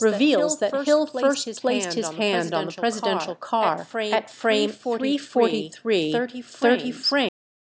echo_0.5.wav